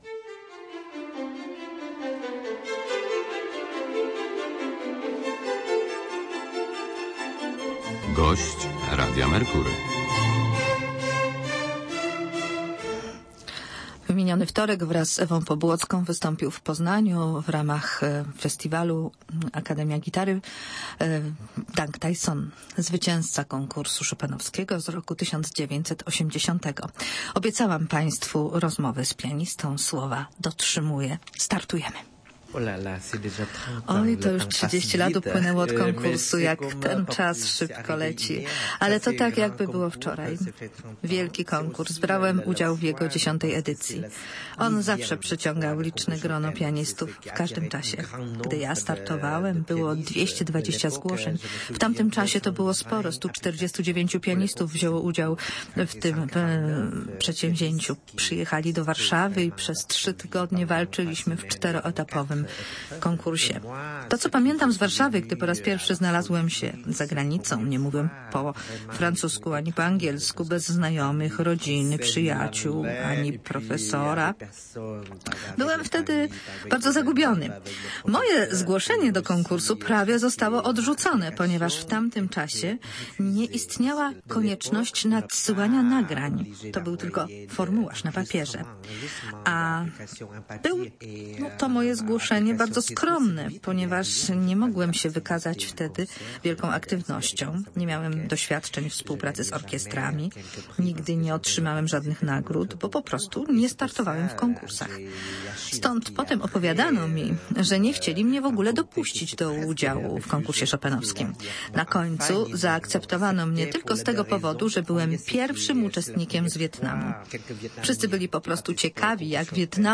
Na naszej stronie rozmowa z Dang Thai Sonem przed jego występem w Poznaniu na koncercie w ramach "Akademii Gitary Polskiej".
jamuxl0268yza2o_dang_thai_son_rozmowa.mp3